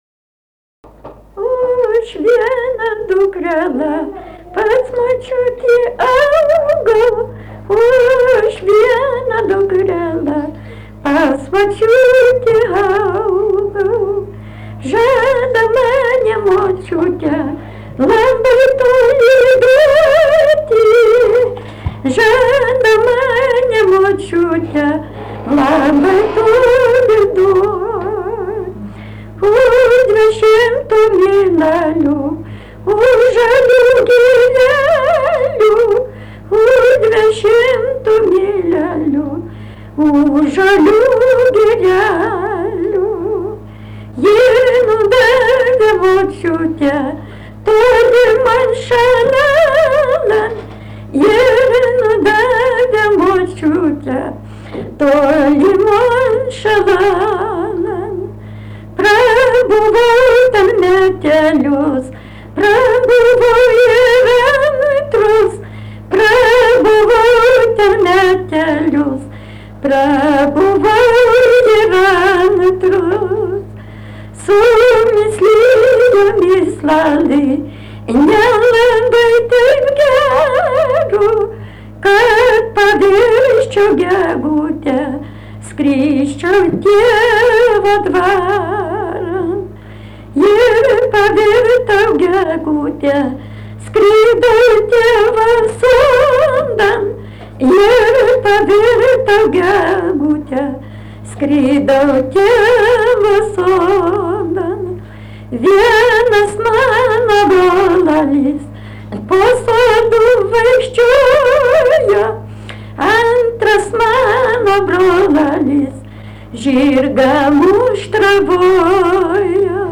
daina